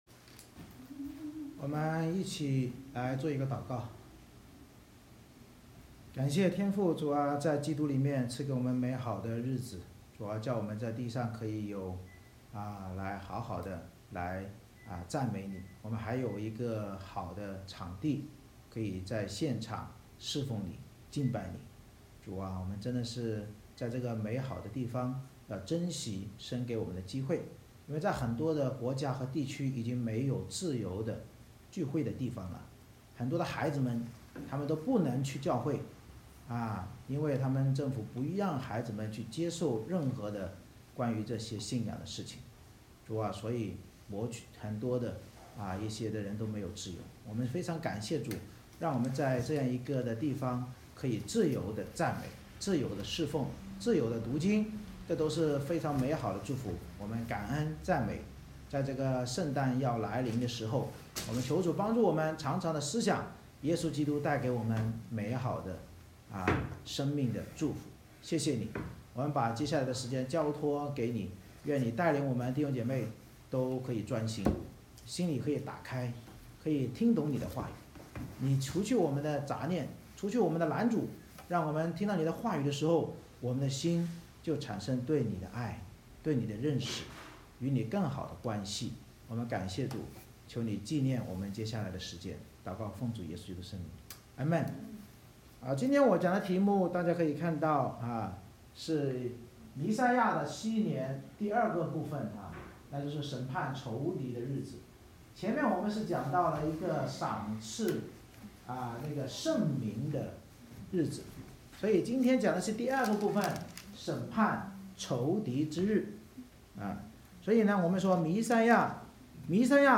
以赛亚书Isaiah 63:1-19 Service Type: 主日崇拜 神藉着先知预言受苦弥赛亚将来治理的禧年，启示我们对弥赛亚的救赎要长存感恩，因为主再临时，祂必向忘恩者和仇敌报仇。